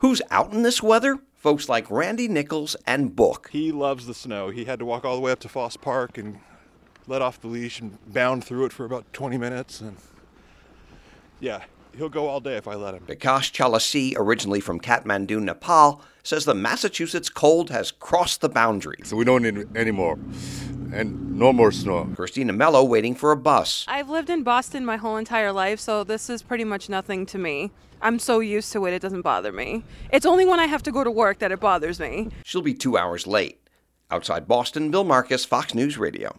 (OUTSIDE BOSTON) FEB 2 – BLUSTERY WIND – BLOWING SNOW – SUB-FREEZING TEMPERATURES…WELCOME TO NEW ENGLAND.